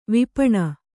♪ vipaṇa